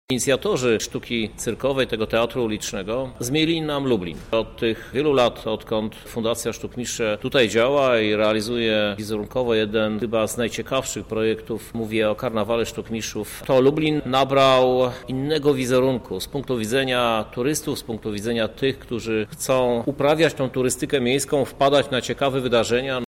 Lublin stanie się stolicą artystów z całego świata. Przed nami Europejska Konwencja Żonglerska– mówi Krzysztof Żuk, prezydent Lublina